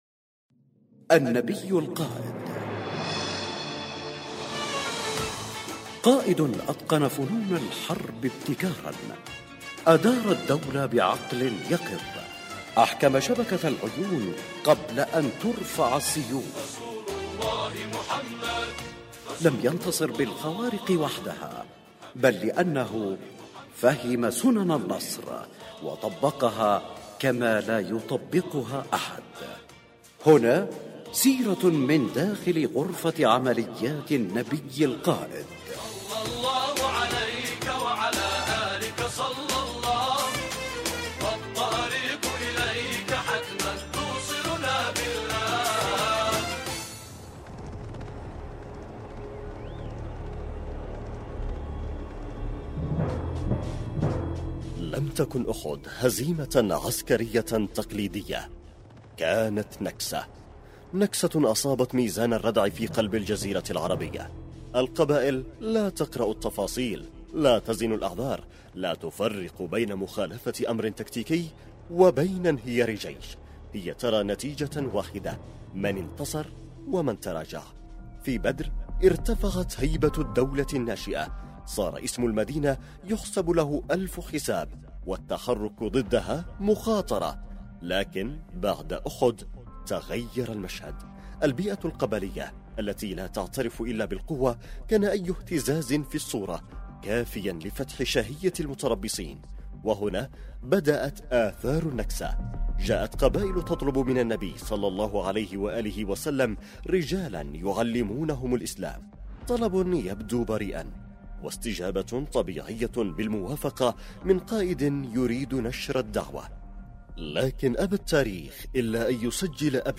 النبي القائد، برنامج إذاعي يقدم الجوانب العسكرية والأمنية في السيرة النبوية للنبي الاكرم صلى الله عليه واله مع الاعتماد بشكل كلي على ما ذكره السيد القائد يحفظه الله في محاضراته خلال رمضان وخلال المولد النبوي الشريف.